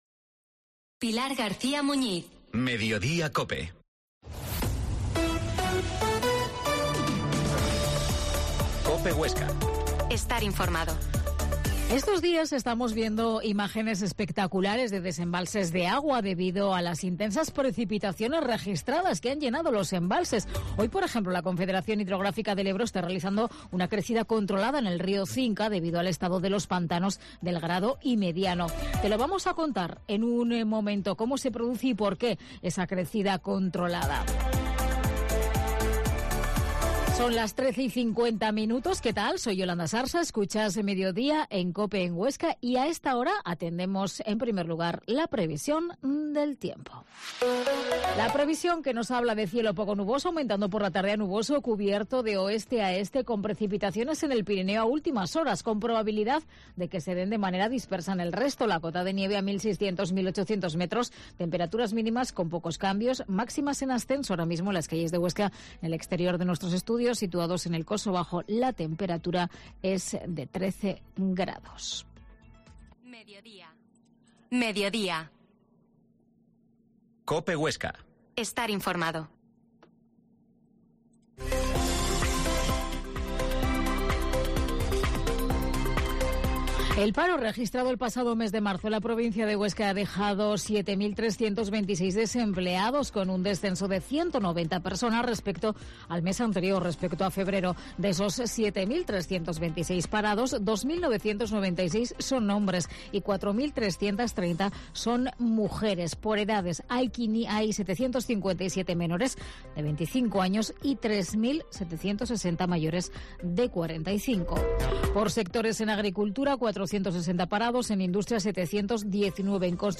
AUDIO: Reportaje sobre la crecida controlada dle río Cinca. Actualidad local